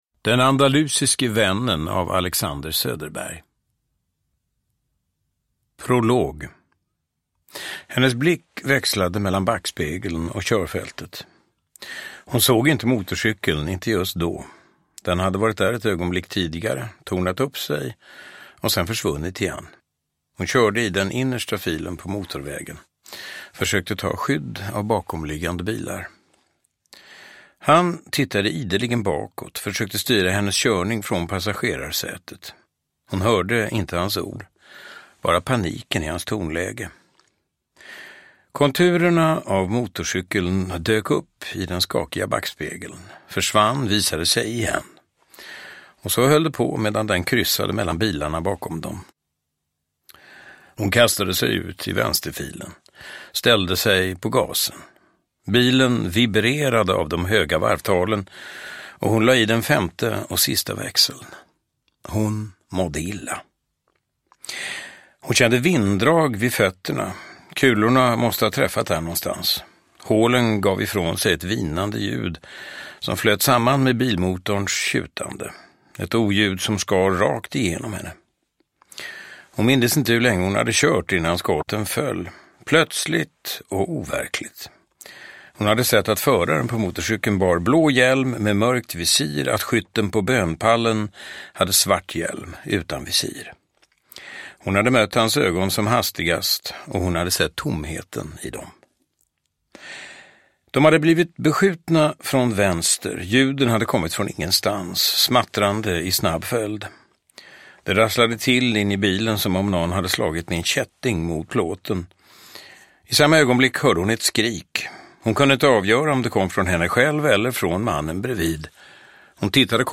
Uppläsare: Johan Rabaeus